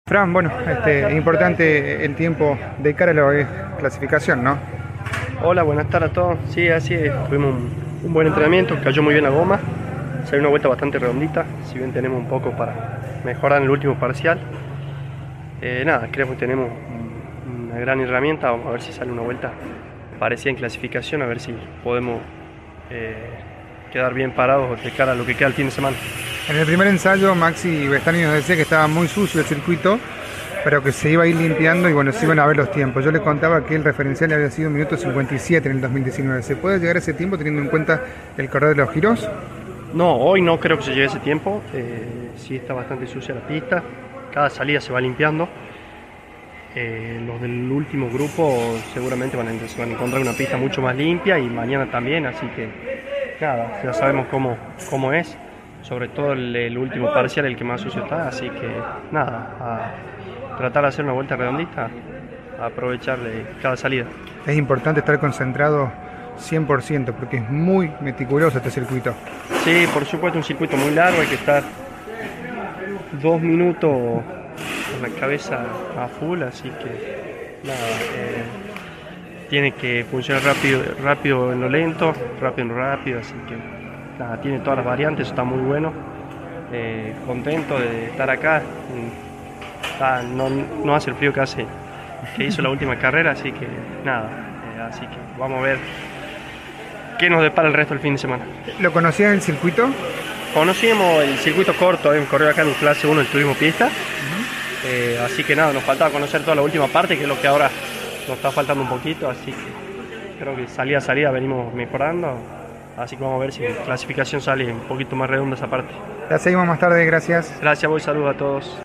El testimonio